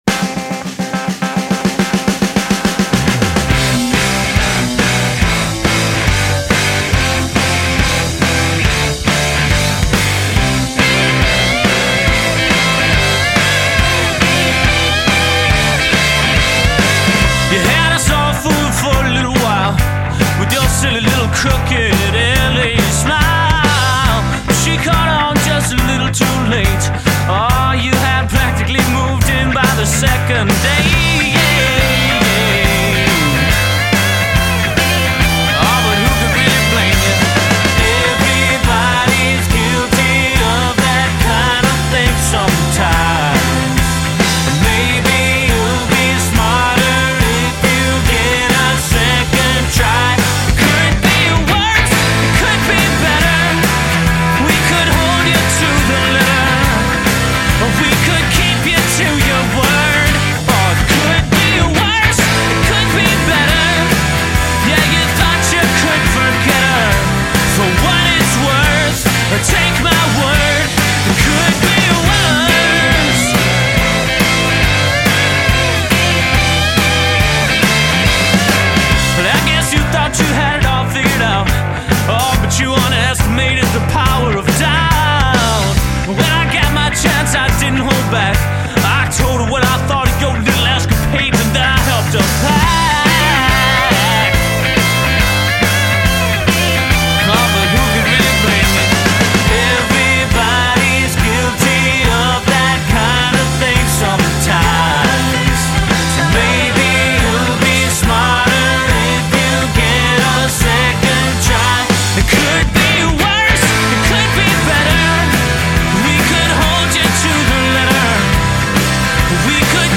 power pop